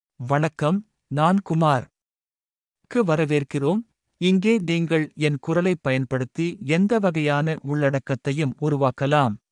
Kumar — Male Tamil AI voice
Kumar is a male AI voice for Tamil (Sri Lanka).
Voice sample
Male
Kumar delivers clear pronunciation with authentic Sri Lanka Tamil intonation, making your content sound professionally produced.